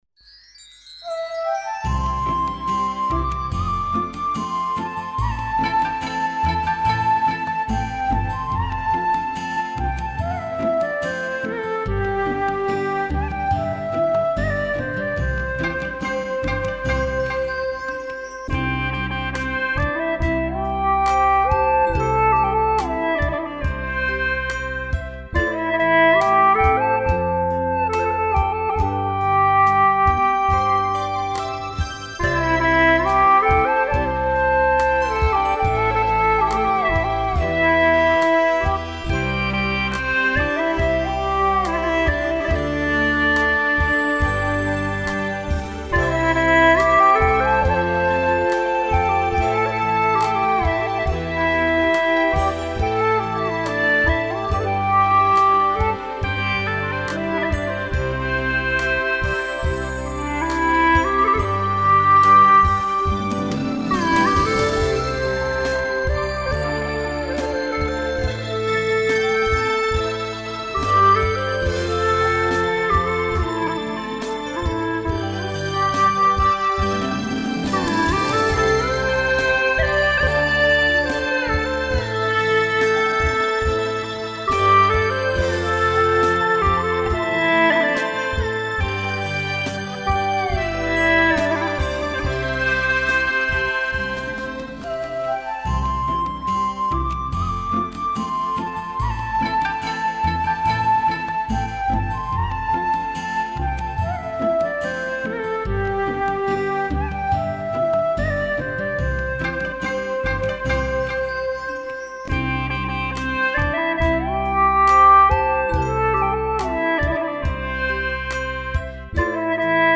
调式 : C 曲类 : 流行
此曲用两支葫芦丝合奏形式完成。